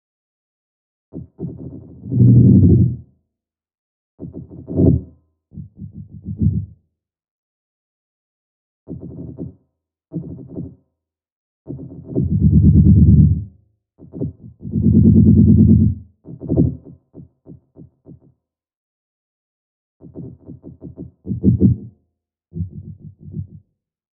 Weird Sounds At Night Fantasy Sfx Sound Effect Download: Instant Soundboard Button